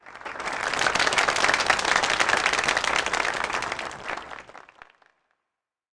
Applause Sound Effect
Download a high-quality applause sound effect.
applause-9.mp3